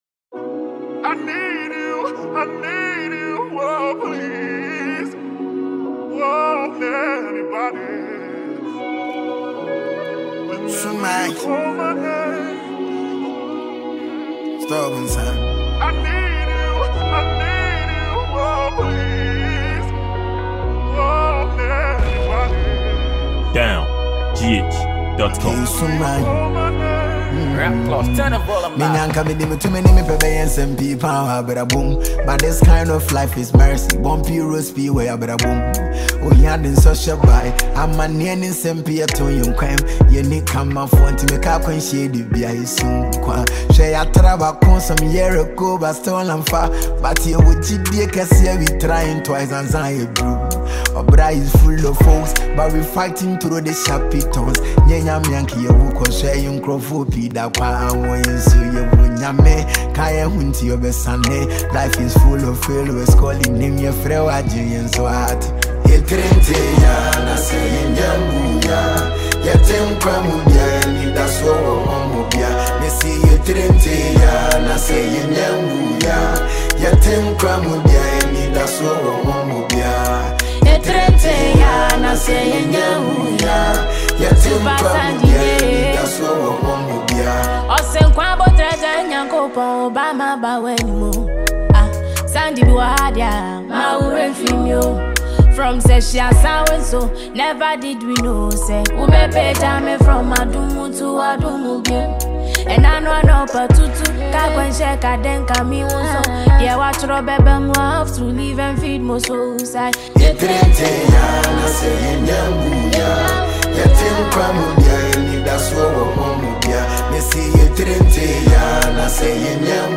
a Ghanaian drip highlife singer